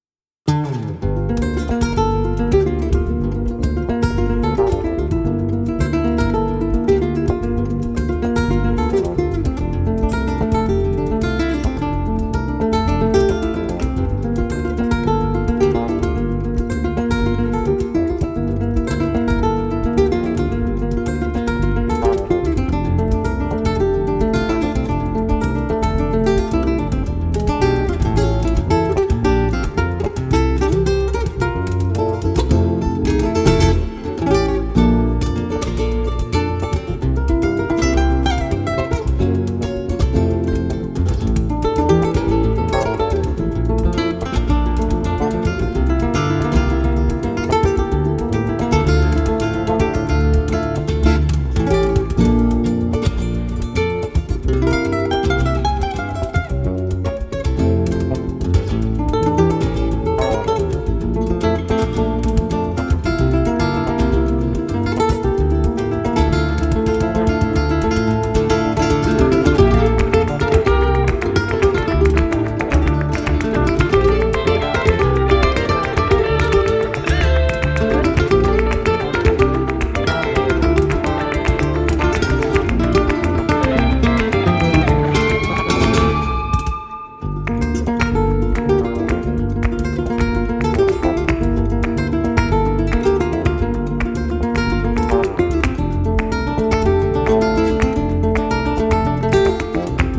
Zurück zu: Flamenco
Rumba